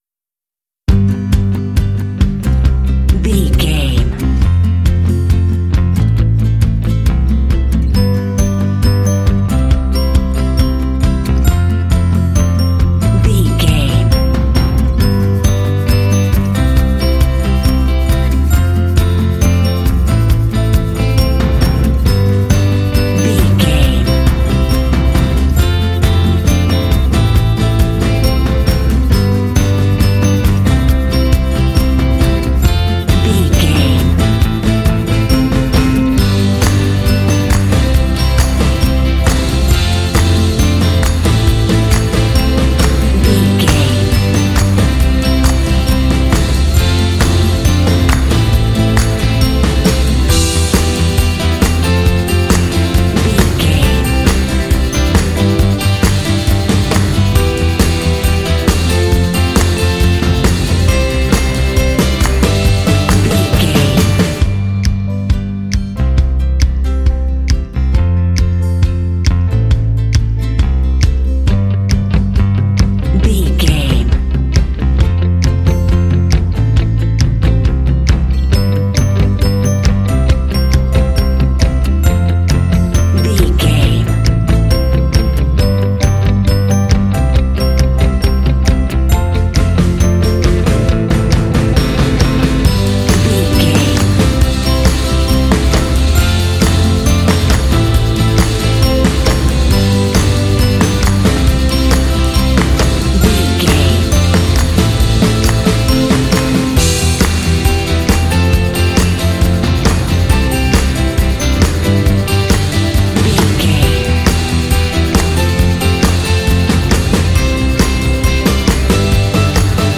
Fun and cheerful indie track with bells and “hey” shots.
Uplifting
Ionian/Major
cheerful/happy
playful
acoustic guitar
electric guitar
bass guitar
percussion
drums
piano
alternative rock